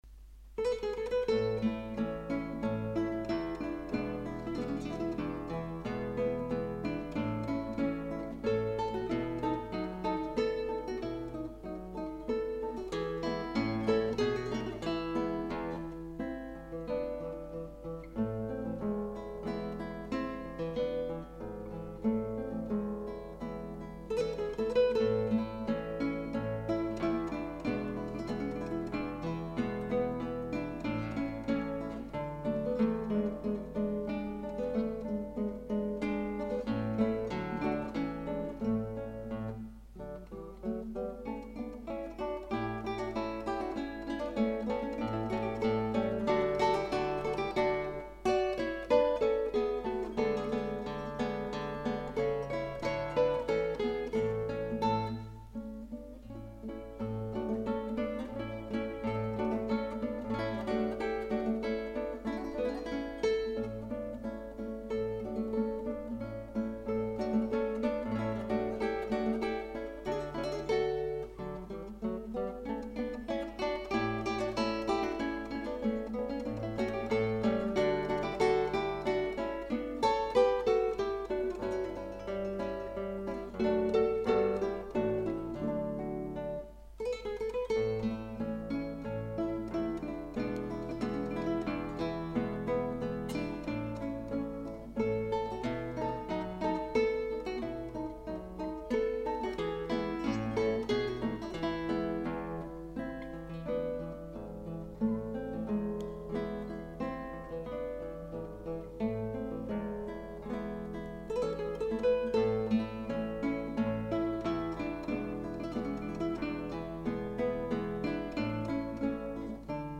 repertoire samples
35. guitar duos